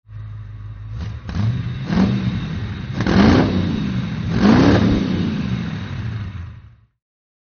Motorsounds und Tonaufnahmen zu De Tomaso Fahrzeugen (zufällige Auswahl)
de Tomaso Mangusta (1970) - Gasstösse
de_Tomaso_Mangusta_1970_-_Gasstoesse.mp3